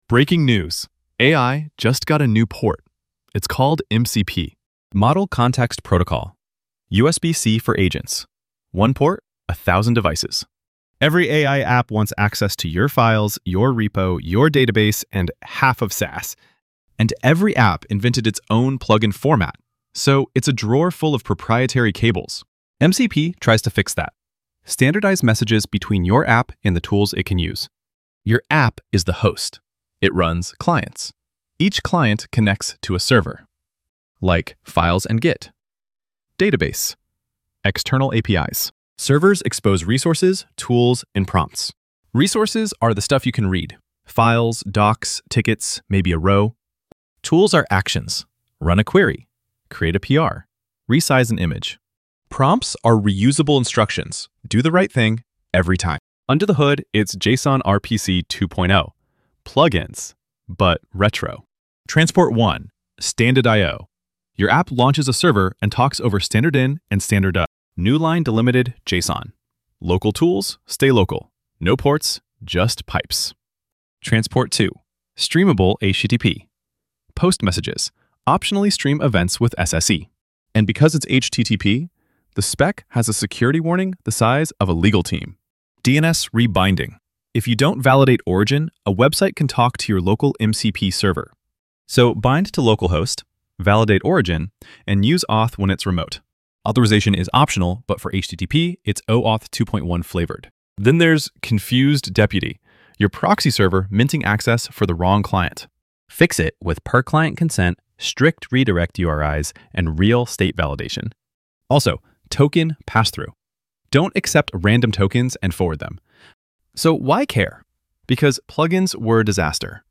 Latest published voiceover-only for the “MCP is USB‑C for agents” episode.